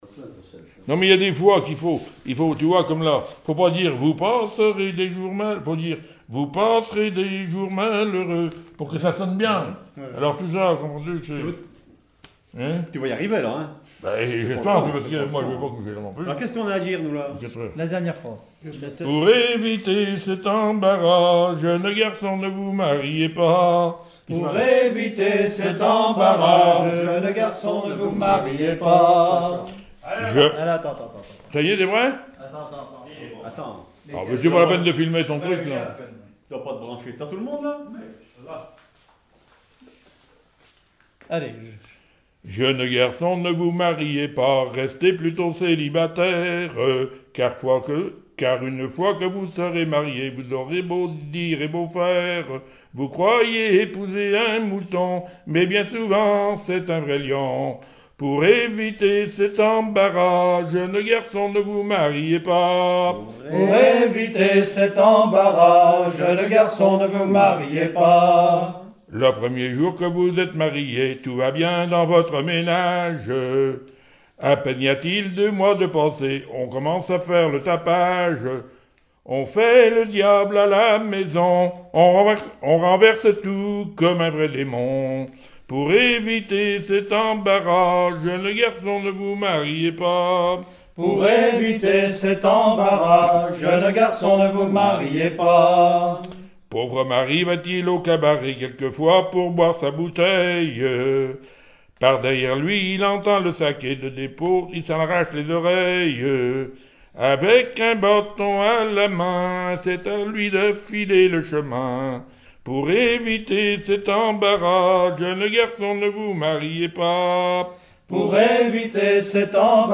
Mémoires et Patrimoines vivants - RaddO est une base de données d'archives iconographiques et sonores.
Genre strophique
Collectif cantonal pour le recueil de chansons
Pièce musicale inédite